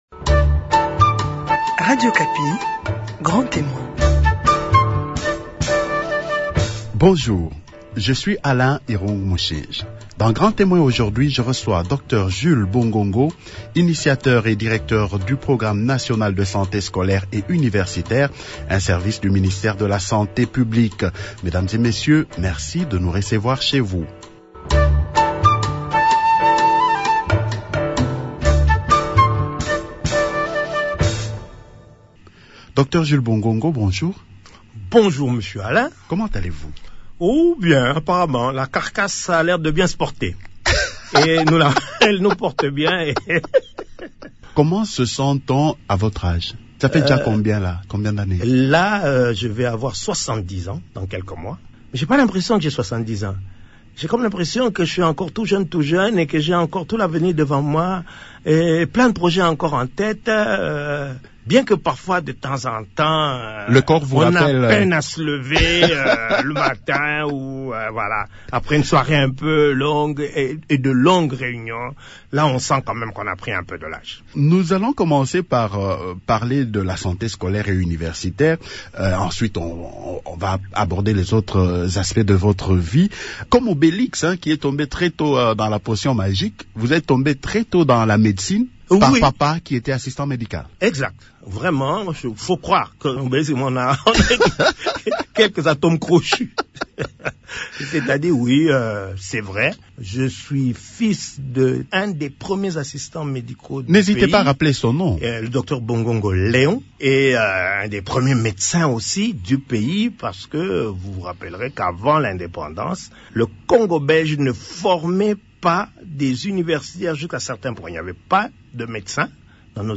Dans son entretien